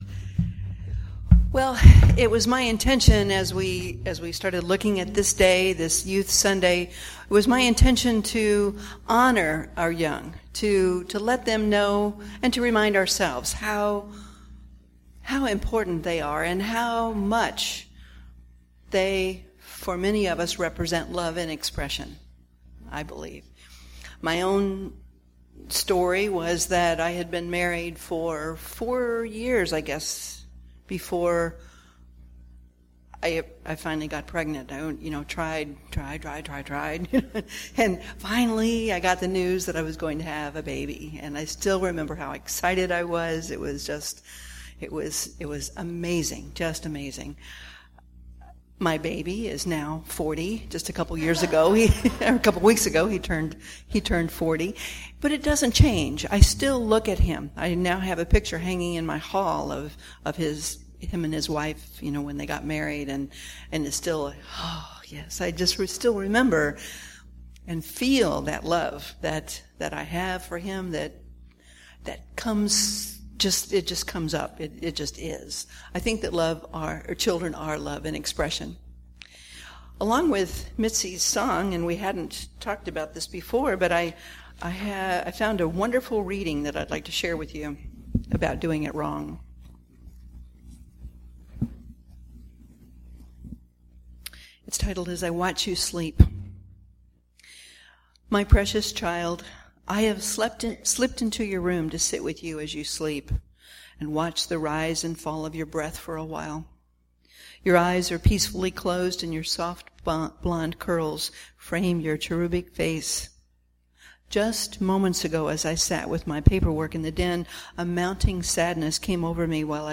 Series: Sermons 2015